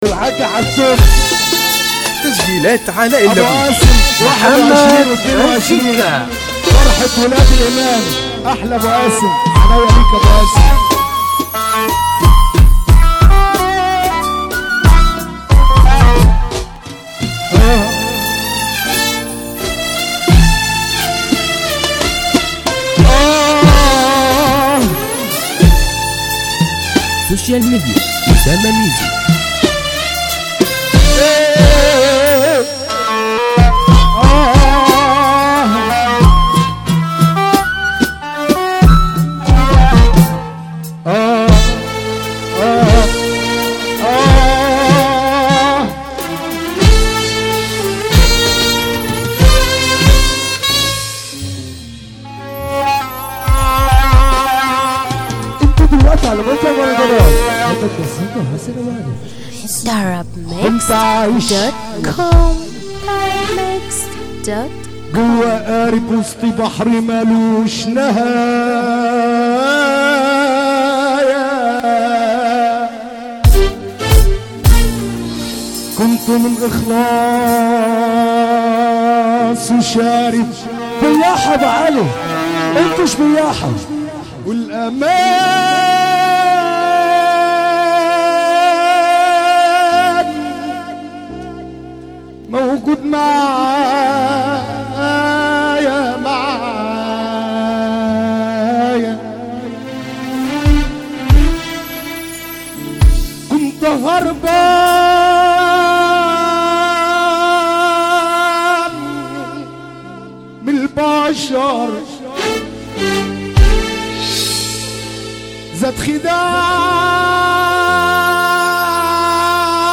النوع : shobeiat